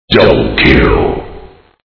sound_effects
double-kill-1.mp3